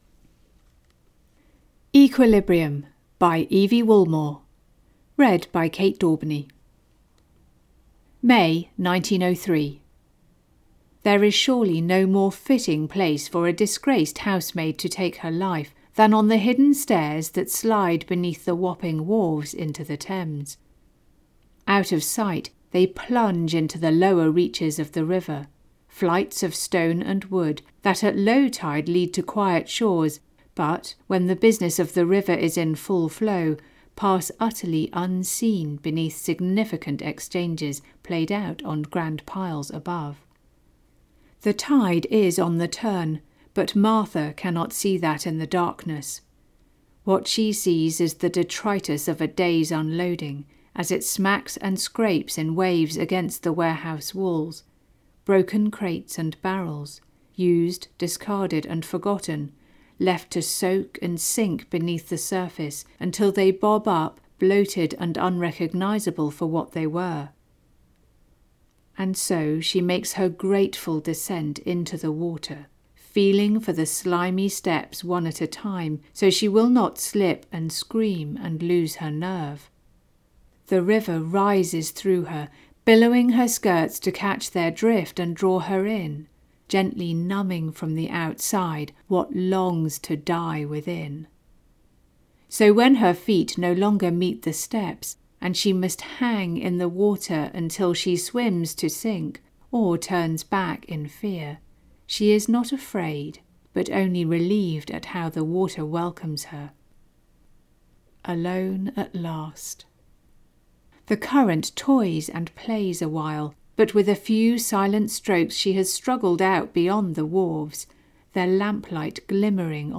Audiobook sample
equilibrium-by-evie-woolmore-audiobook-sample.mp3